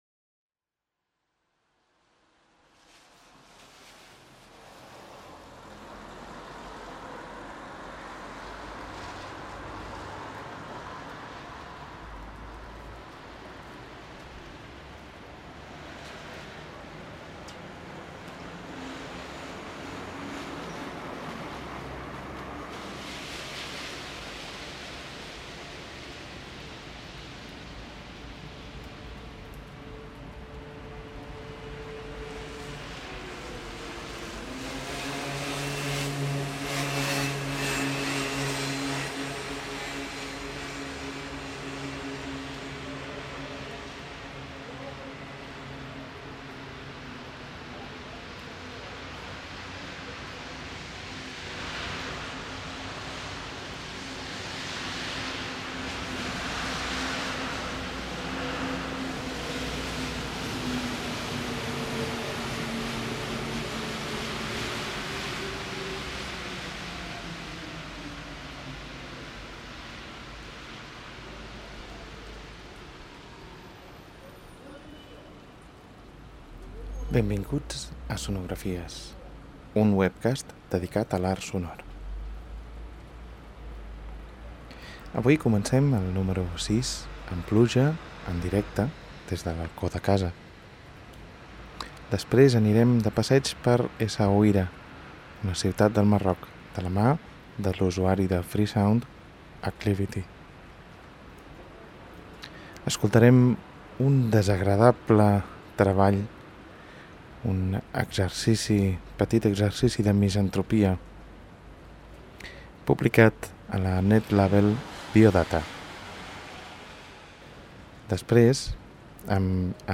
És per això que el webcast el comencem amb una fina pluja enregistrada des del balcó de casa.
Anirem de visita a Essaouira, una ciutat de la costa atlàntica del Marroc. Guiats per un usuari de FreeSound, caminarem per diferents indrets de la ciutat, a l’estil dels “sound seeing” podcasts.
La versió original és monoaural, però, què dimonis!
Potser tindràs la sensació que el buffer està ple i el sistema no pot reproduir correctament la música electrominimalista amb errors temporals.